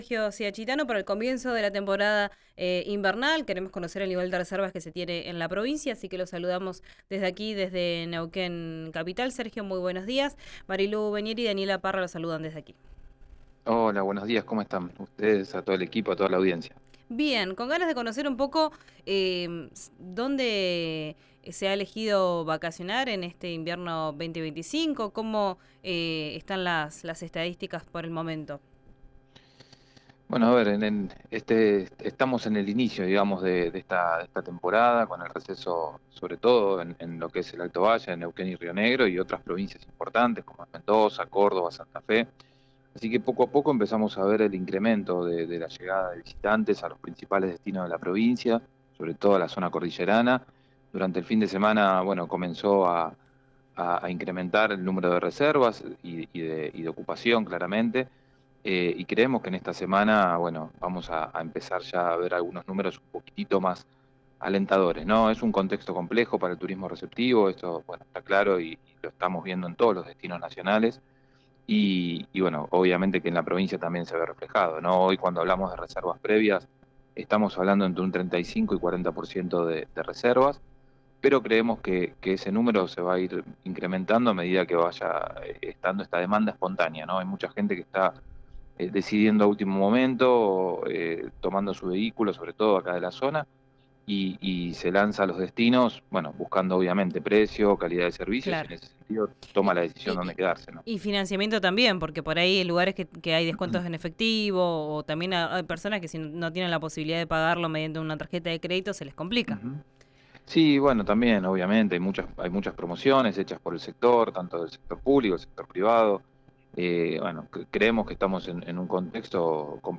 en diálogo con Río Negro Radio.